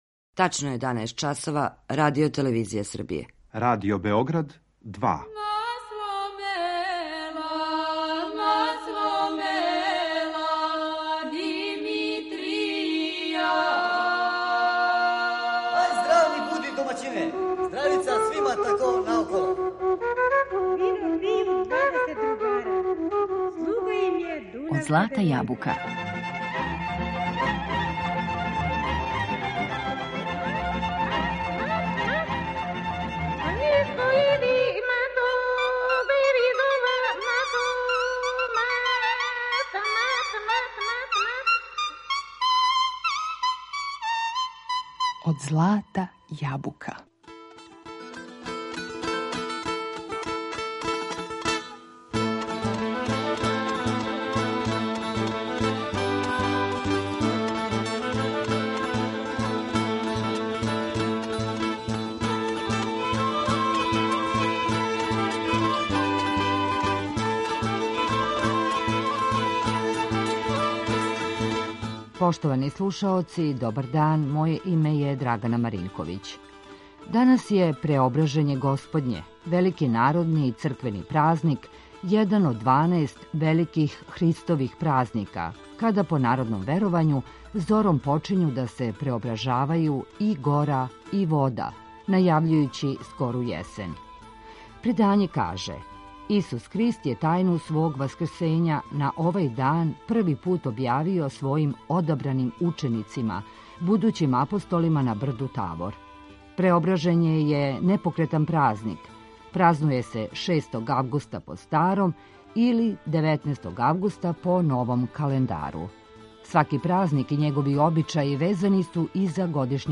У данашњој емисији Од злата јабука говорићемо о бројним народним веровањима, легендама, предањима и обичајима који су везани за данашњи празник, уз добру изворну музику.